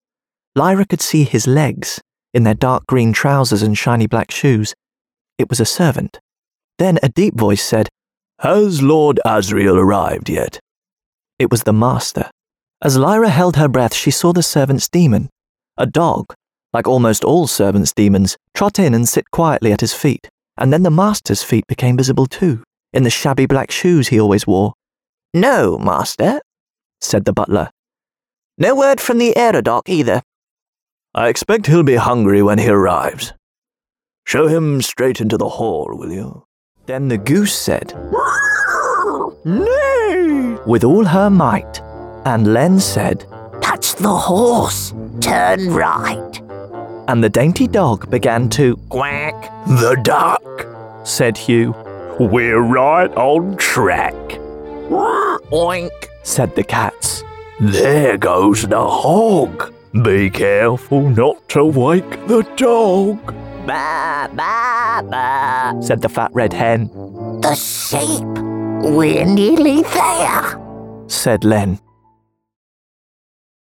Bright, conversational and characterful British voice.
Audiobook Reel
My voice has been described as characterful, bright and engaging, with a youthful and conversational tone.
I record and deliver professional quality audio from my home studio in London, using high quality equipment and software from leading brands including Rode, Apple, Aston, Focusrite, Sennheiser, Izotope and Sony.
Audiobook Reel.mp3